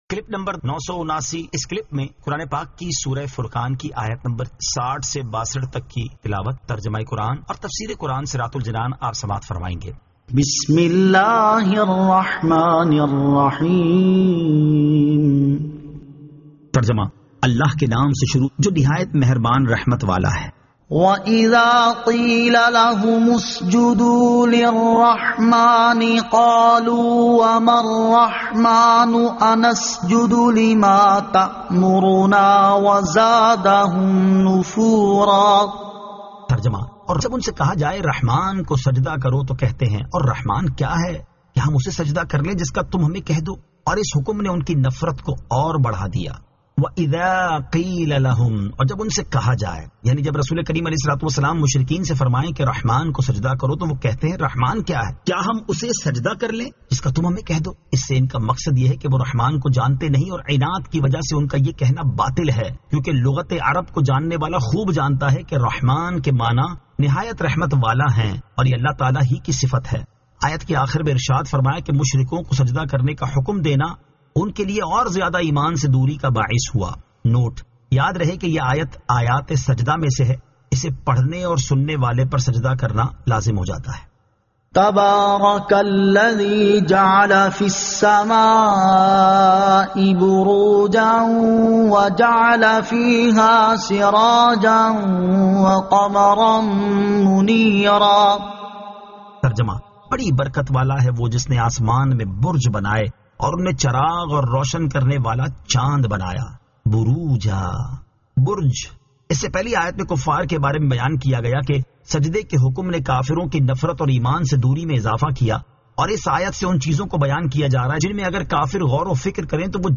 Surah Al-Furqan 60 To 62 Tilawat , Tarjama , Tafseer